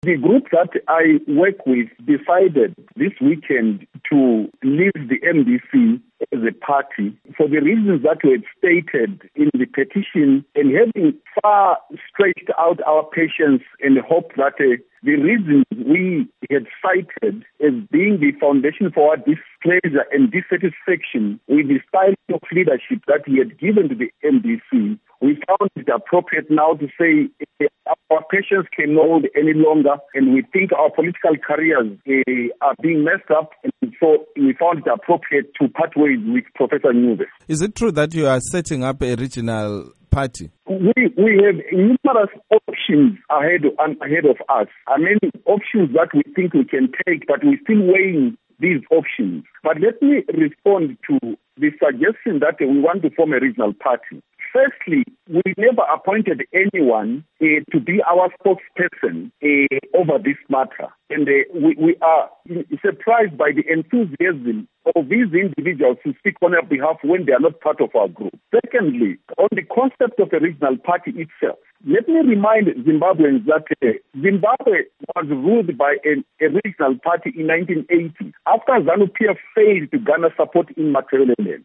Interview With Moses Mzila Ndlovu